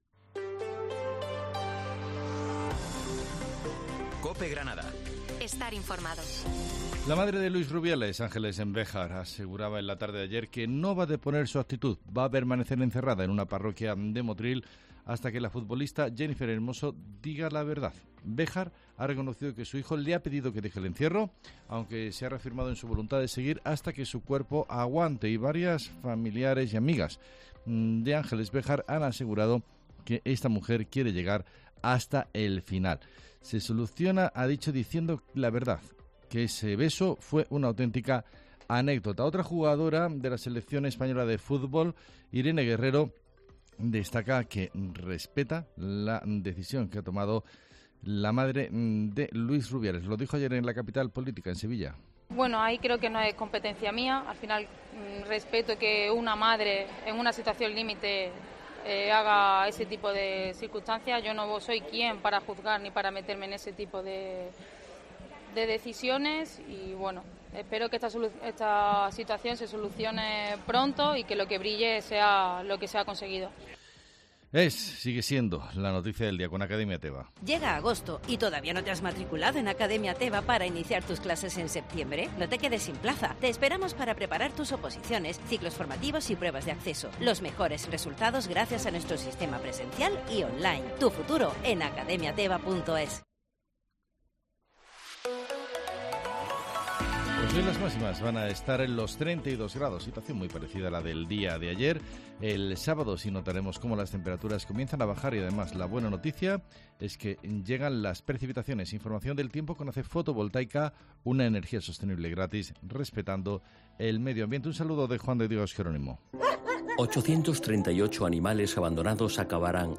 Herrera en COPE Granada, Informativo del 30 de septiembre